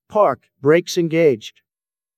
parked-brakes-engaged.wav